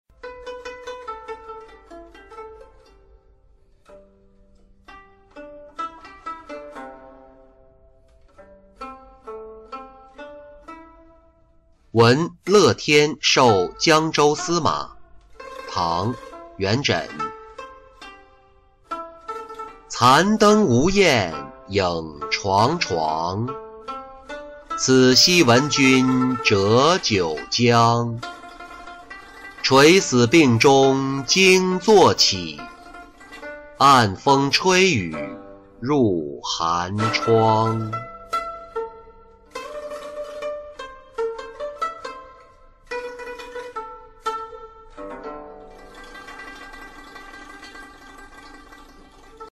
闻乐天授江州司马-音频朗读